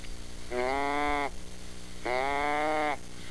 Whitetail Deer Sounds
Fawn Bleat
It is a call that a young deer makes to keep up with their's seblings and their mother too.
fawnbleat.wav